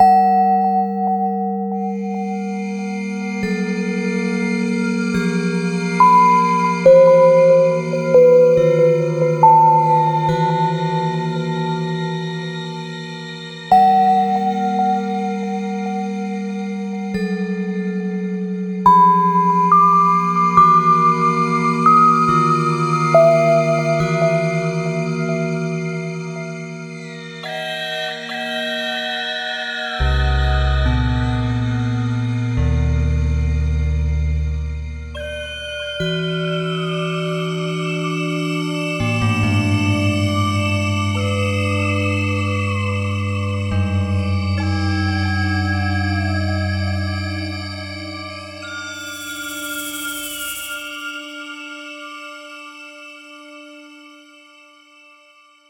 暗い楽曲
【イメージ】ホラー など